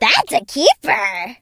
colette_hurt_vo_06.ogg